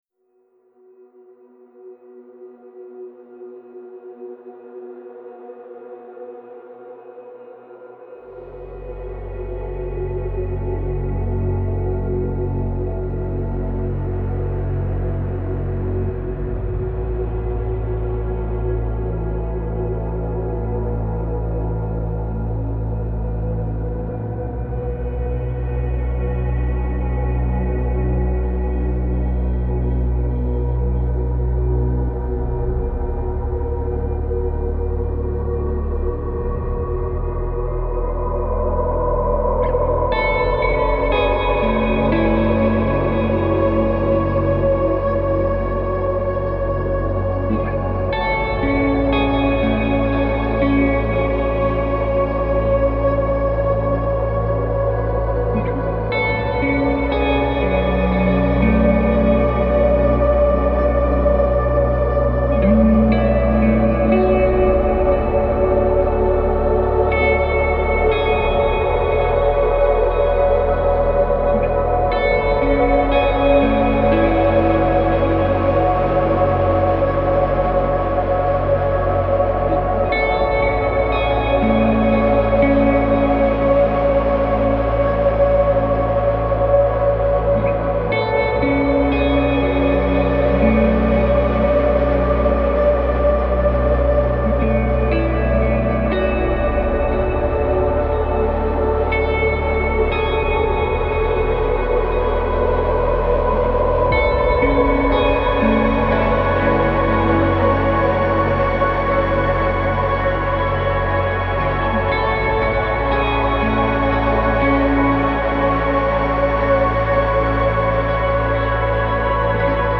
پست راک , عمیق و تامل برانگیز , گیتار الکترونیک
موسیقی بی کلام آرامبخش